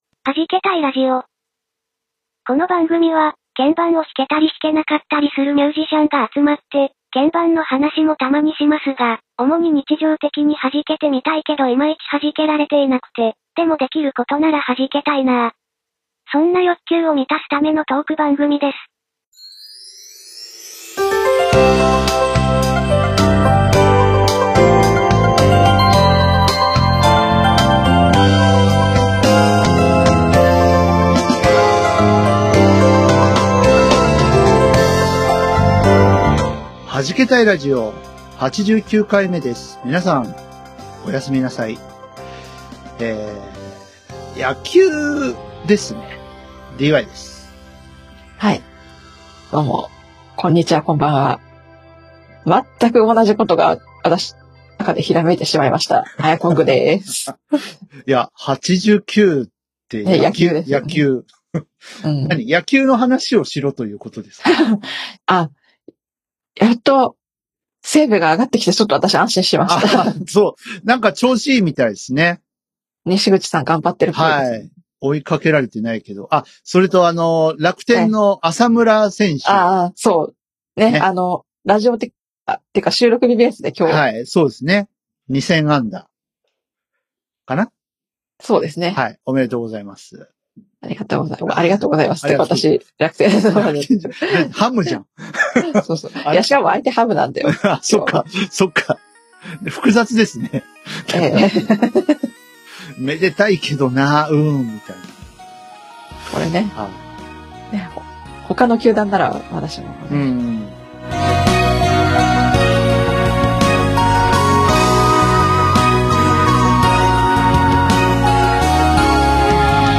鍵盤を弾けたり弾けなかったりする３人のミュージシャンが、日常生活で弾けられないけど弾けたい、そんな欲求を満たすトーク番組。